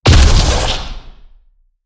WULA_MW_Mass_Drivers_Shootingsound.wav